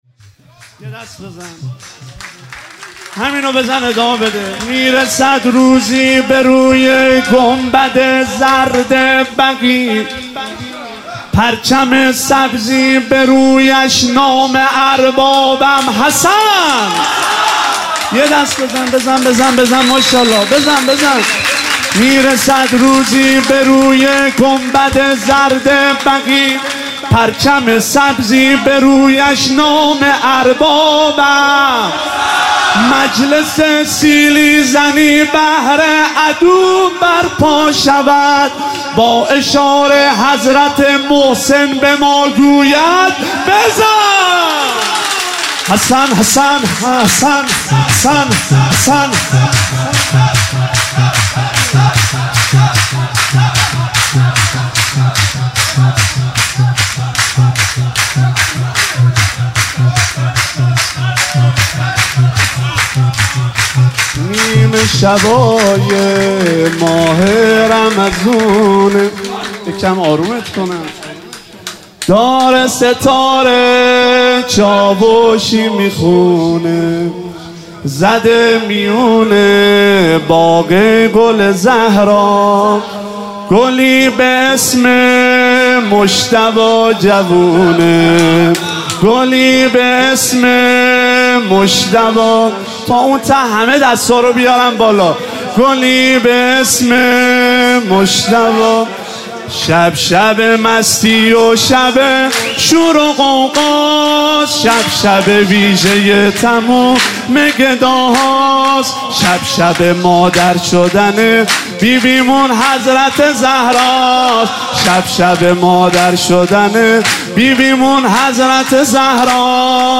عنوان جشن ولادت امام حسن مجتبی علیه السلام – شب پانزدهم ماه مبارک رمضان ۱۳۹۸
برگزار کننده هیئت حسین جان علیه السلام گرگان
سرود می رسد روزی به روی گنبد زرد بقیع